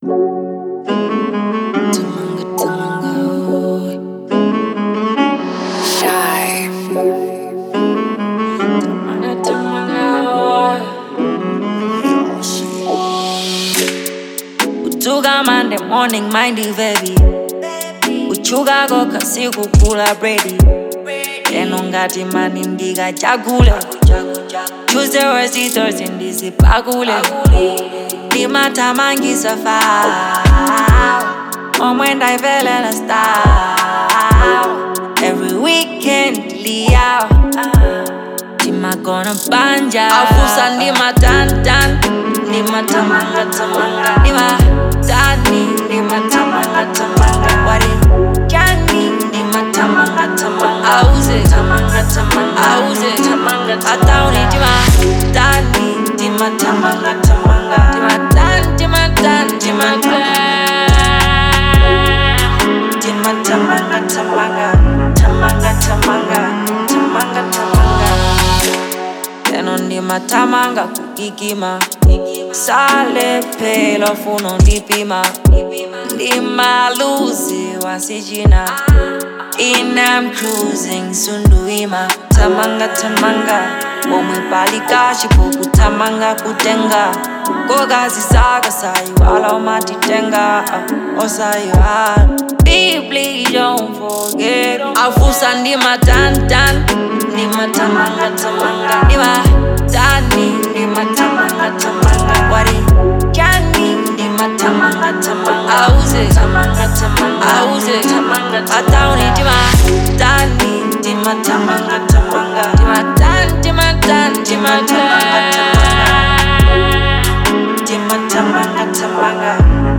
Genre : Drill/Hiphop